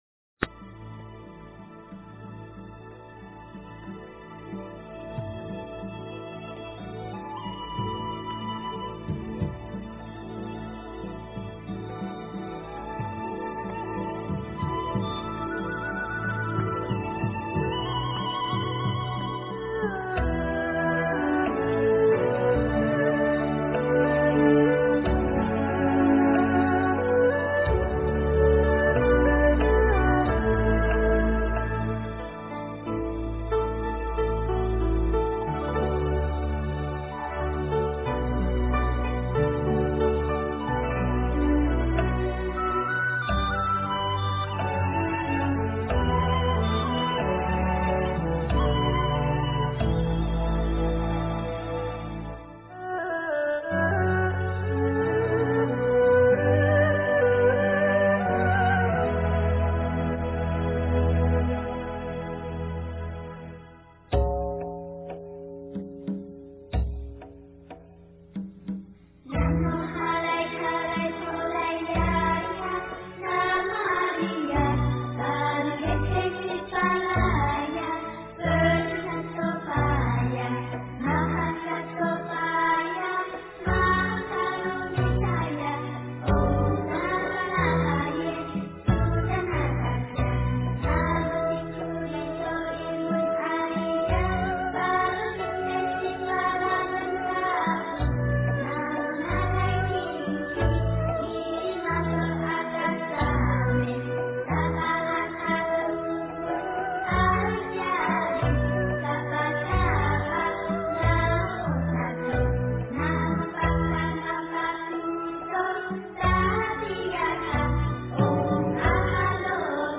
大悲咒-古梵音 诵经 大悲咒-古梵音--童音 点我： 标签: 佛音 诵经 佛教音乐 返回列表 上一篇： 大悲咒 下一篇： 般若波罗密多心经 相关文章 《妙法莲华经》授记品第六 《妙法莲华经》授记品第六--佚名...